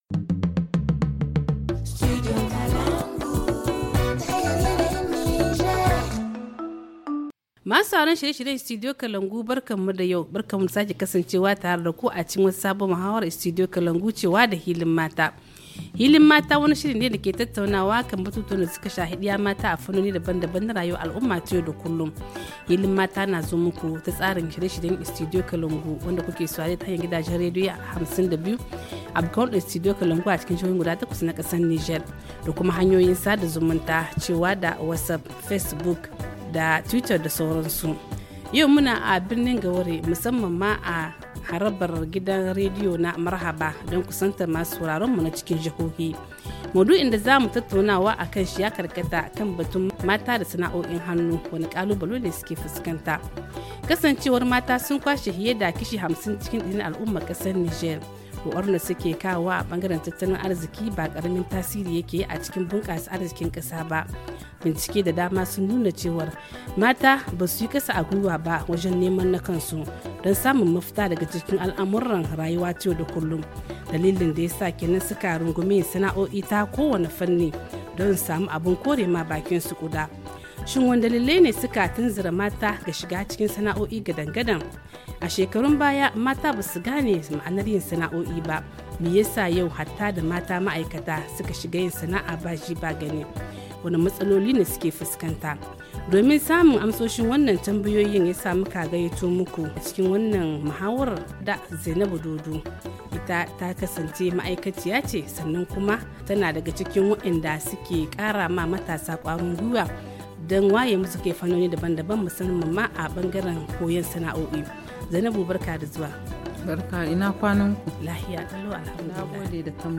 restauratrice HA Le forum en haoussa https